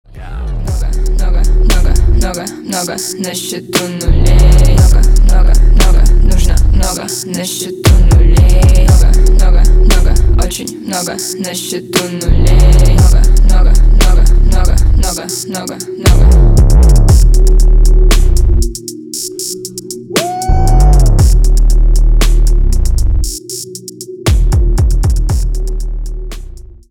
русский рэп
женский рэп
Bass